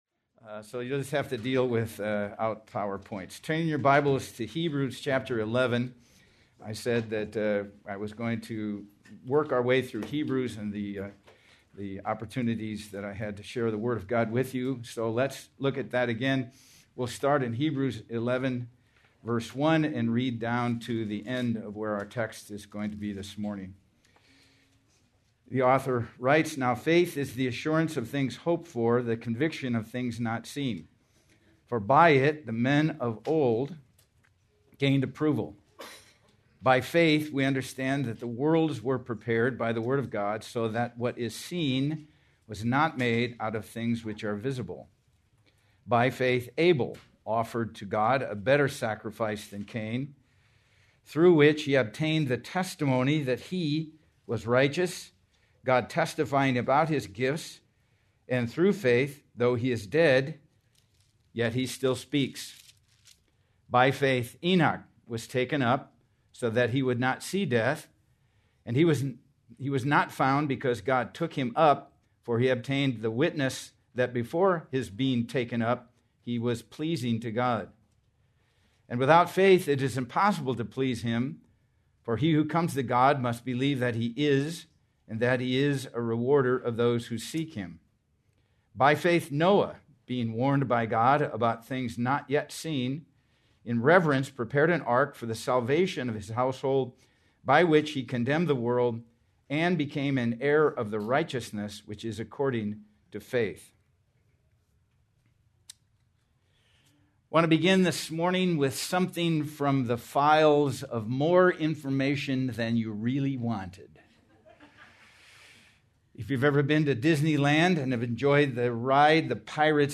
March 29, 2026 - Sermon